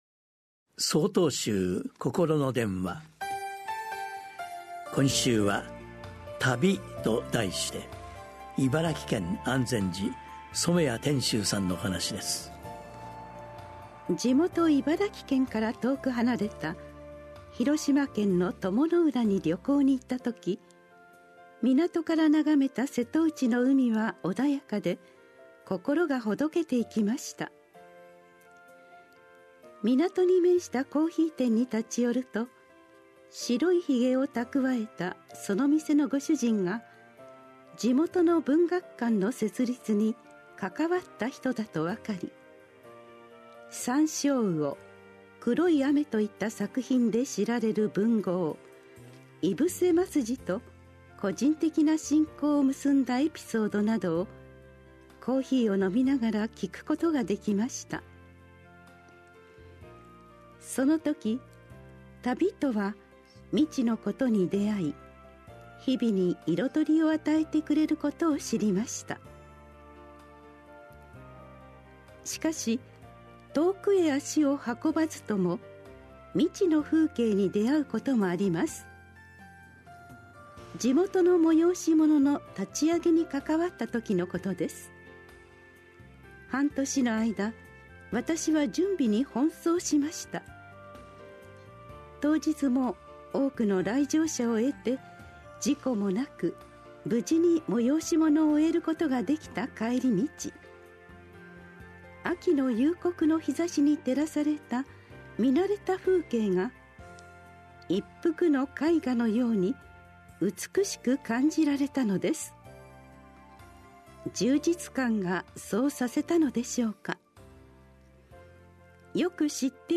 心の電話（テレホン法話）1/14公開『旅』 | 曹洞宗 曹洞禅ネット SOTOZEN-NET 公式ページ